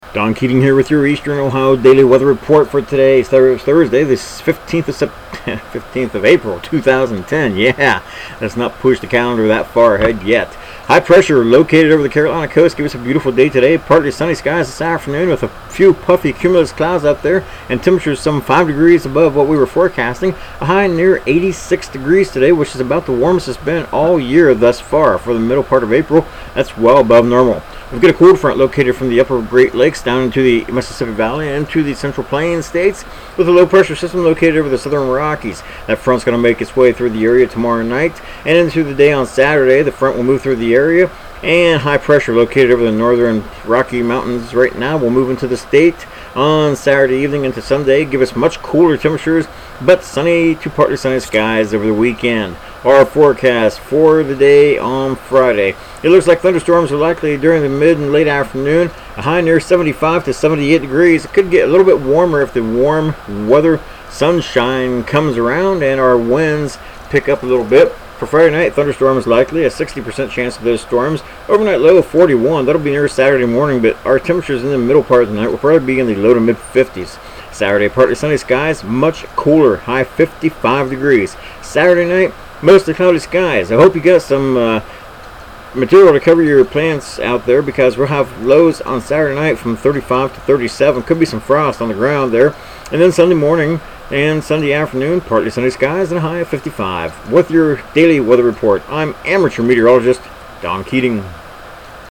Tags: Weather Ohio Newcomerstown Forecast Report